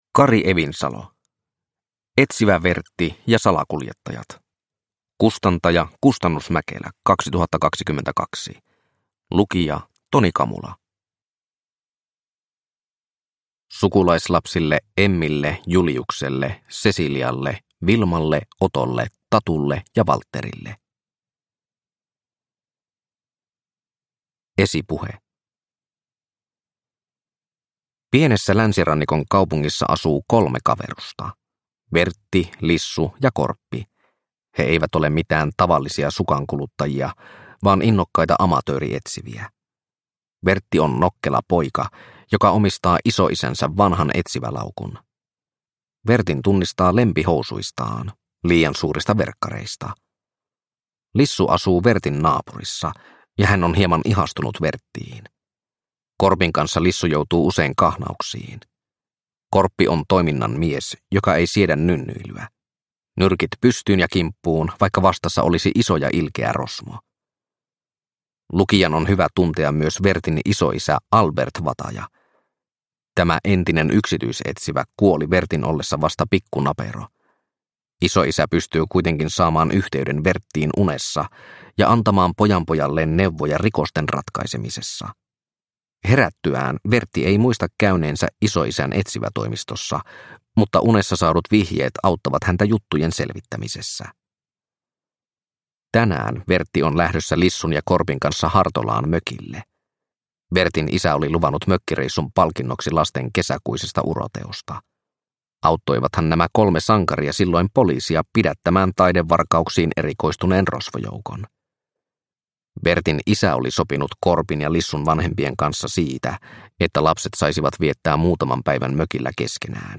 Etsivä Vertti ja salakuljettajat – Ljudbok – Laddas ner